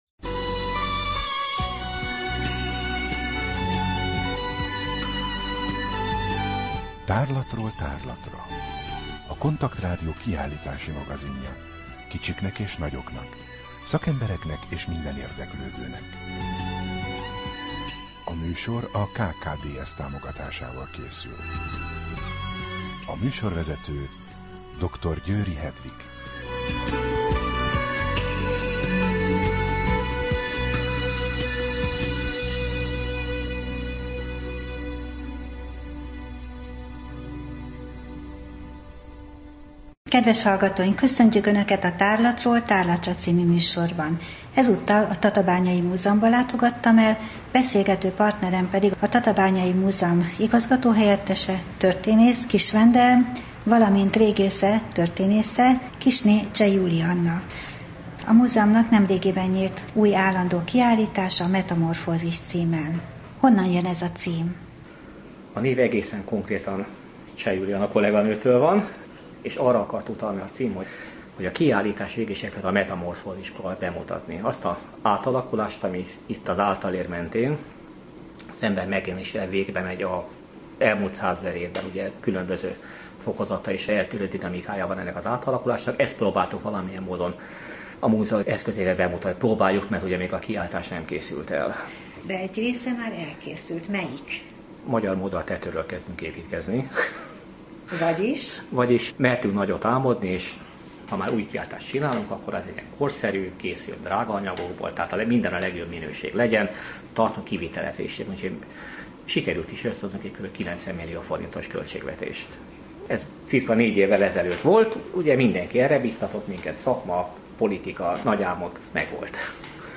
Rádió: Tárlatról tárlatra Adás dátuma: 2014, December 11 Tárlatról tárlatra / KONTAKT Rádió (87,6 MHz) 2014. december 11. A műsor felépítése: I. Kaleidoszkóp / kiállítási hírek II. Bemutatjuk / Tatábányai Múzeum A műsor vendége